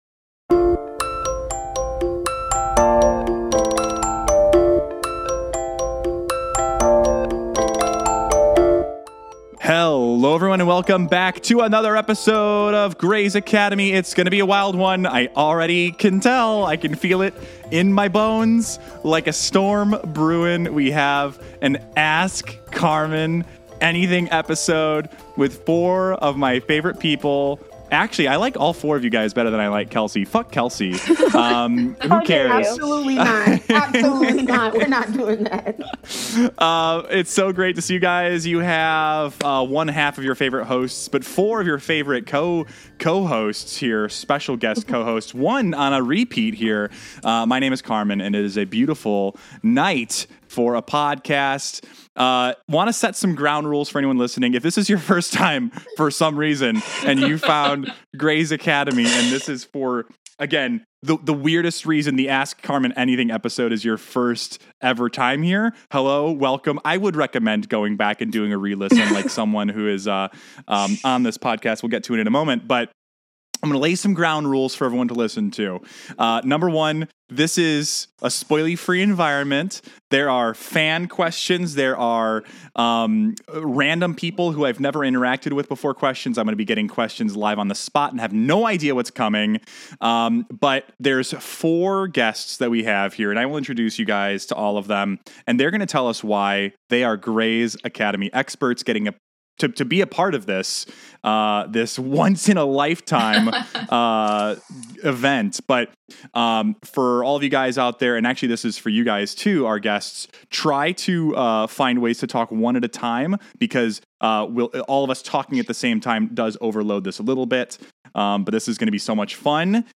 4 special Grey's Academy listeners come on and co-host